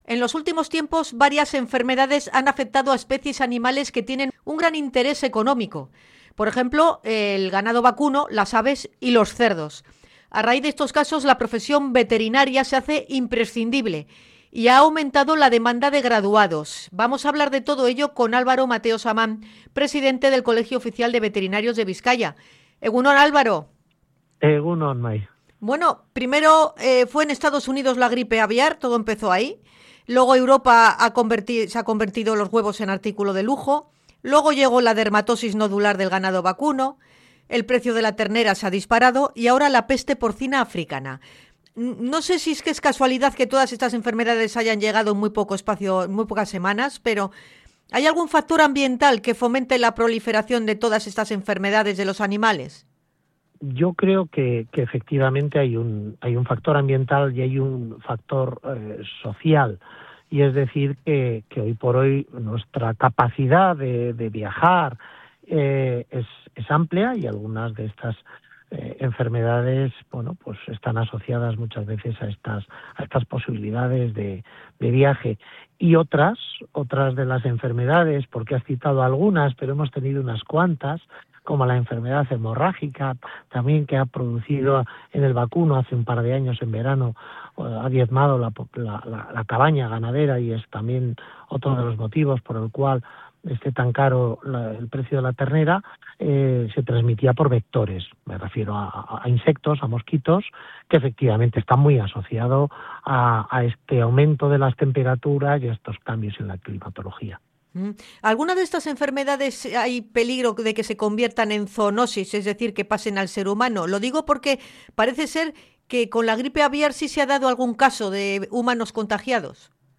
INT.-VETERINARIOS-Y-ZOONOSIS.mp3